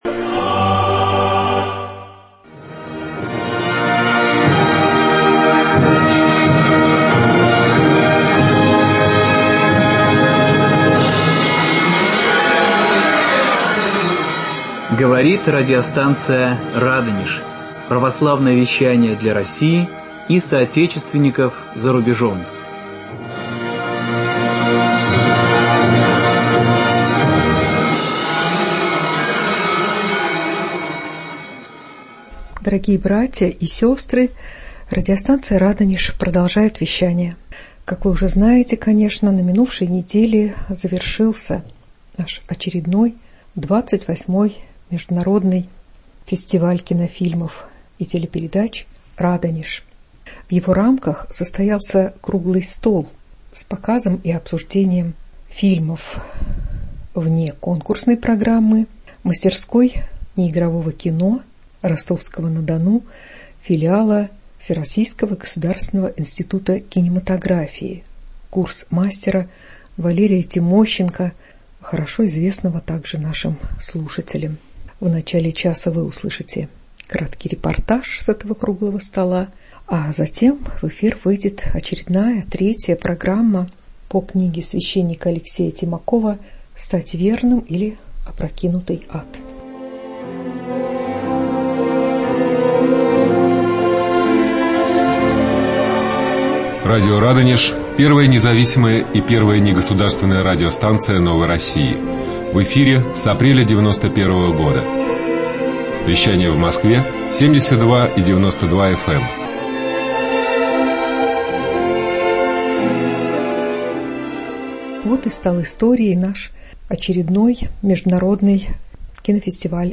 Репортаж
На этот раз состоялись мастер-класс и обсуждение дебютов будущих мастеров.